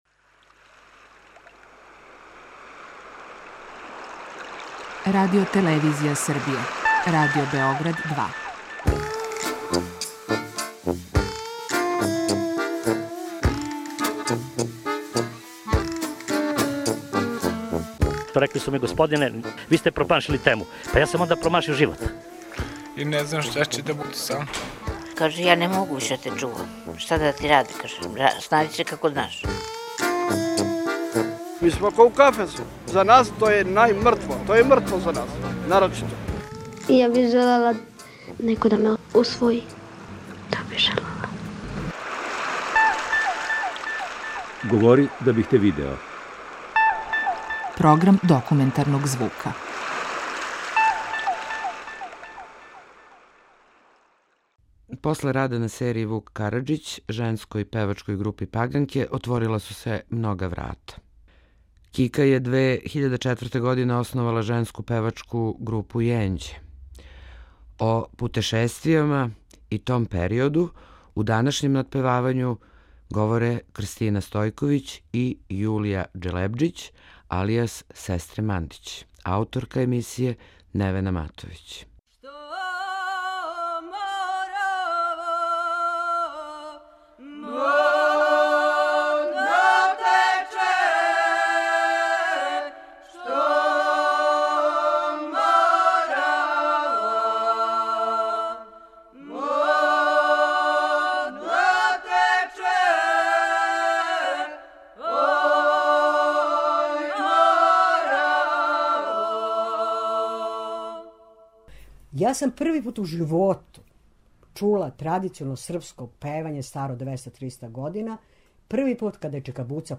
Документарни програм
*У емисији сте слушали снимке женске певачке групе „Паганке" који се чувају у Тонском архиву Радио Београда Насловна фотографија